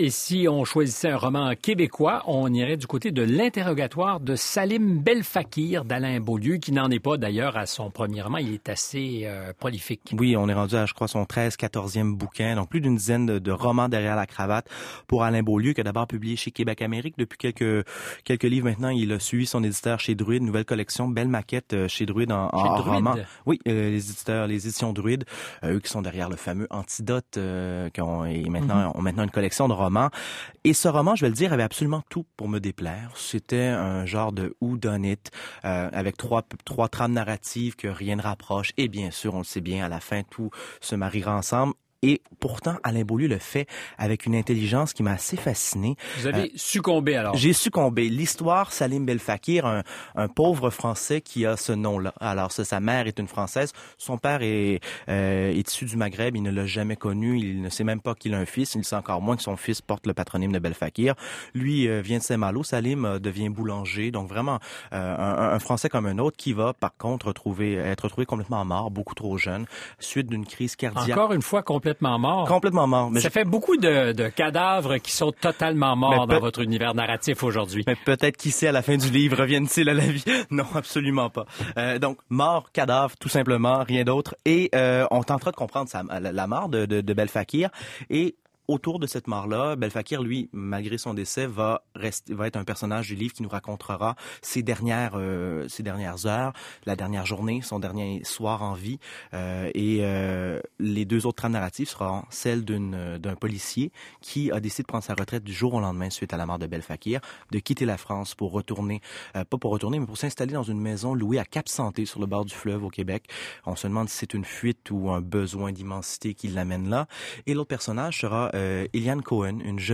On a parlé de L’interrogatoire de Salim Belfakir sur les ondes de Radio-Canada: